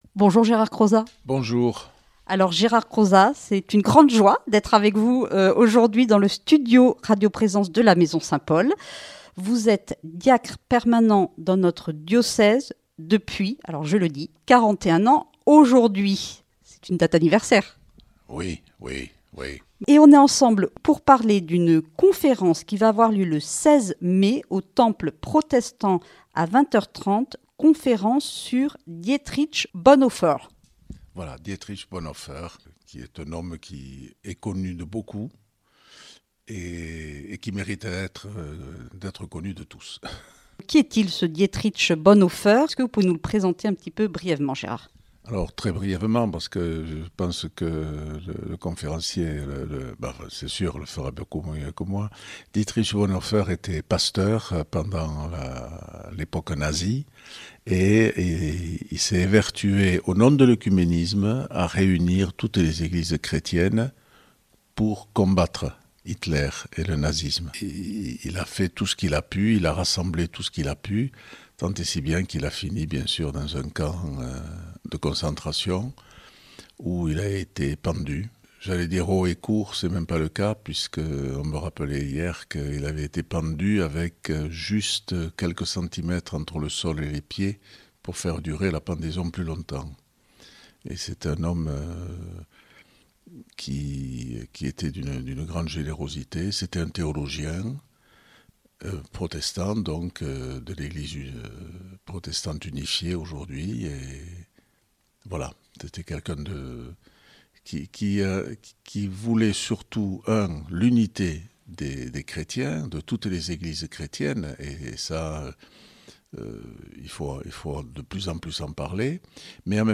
Une émission présentée par
Présentatrice